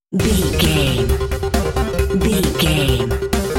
Aeolian/Minor
C♯
Fast
intense
8bit
aggressive
synth
drums
drum machine